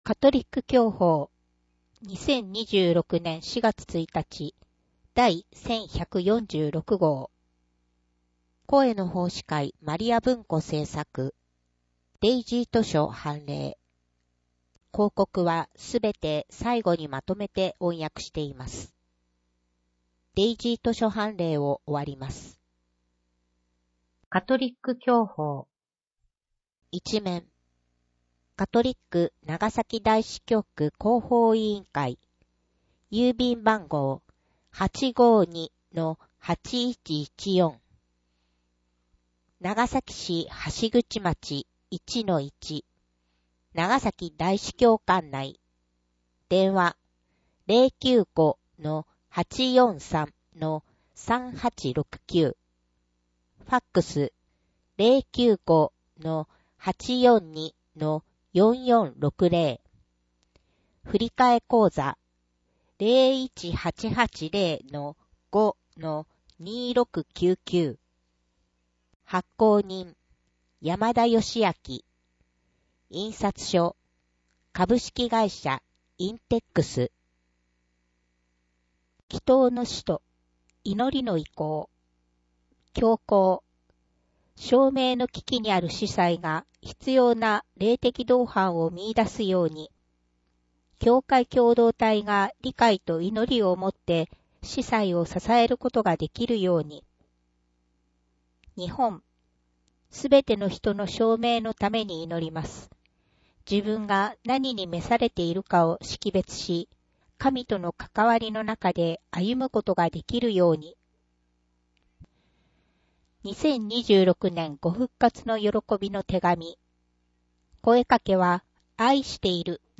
【音声訳】2025年11月号